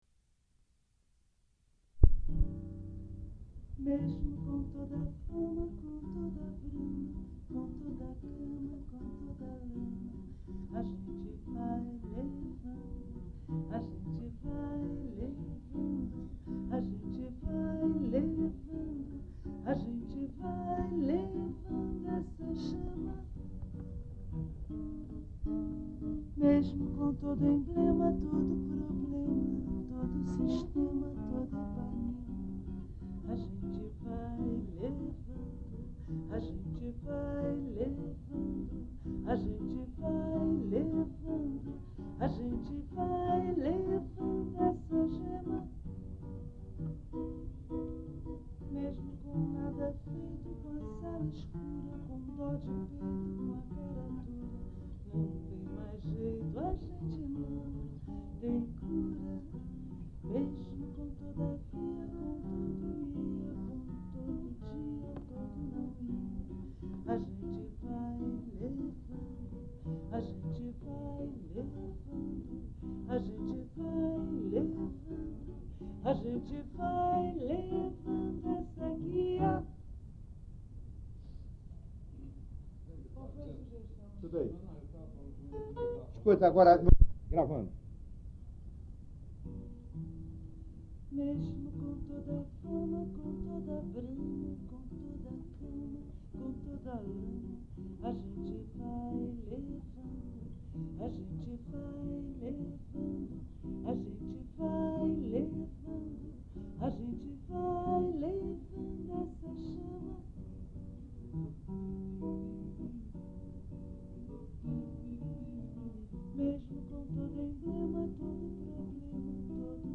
Ensaio para o álbum
violão
Type: Gravação musical